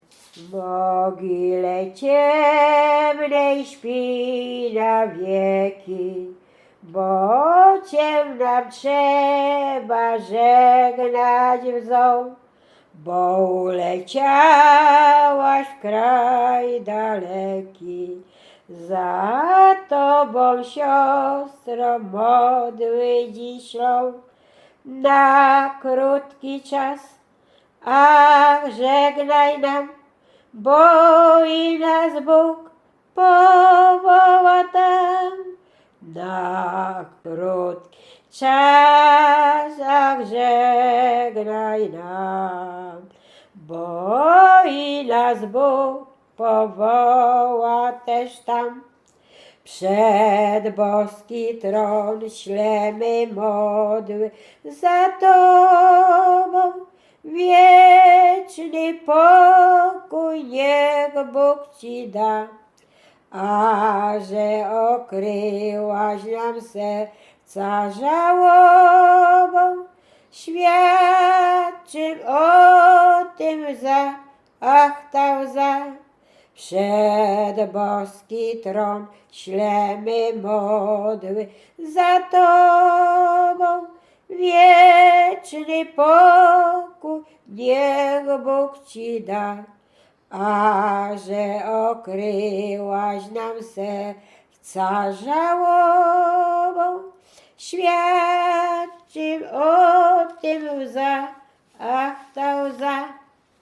Łęczyckie
Pogrzebowa
pogrzebowe nabożne katolickie do grobu